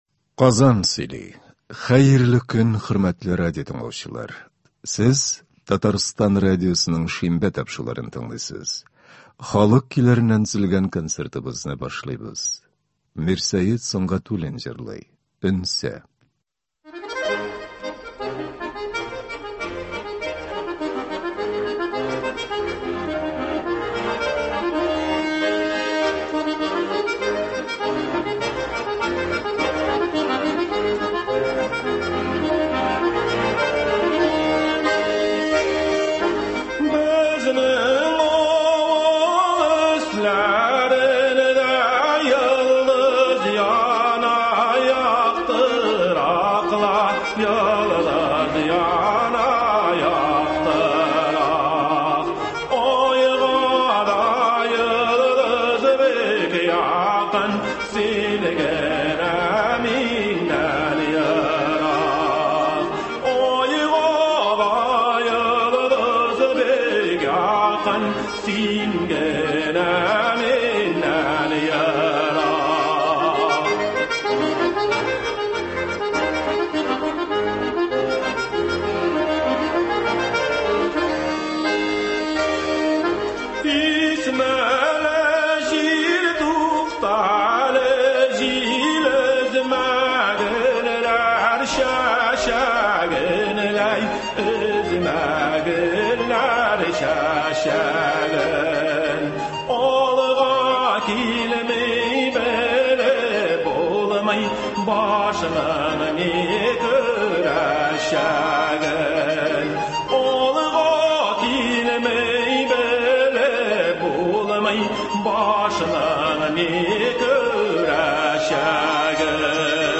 Татар халык җырлары
Концерт (25.09.2021)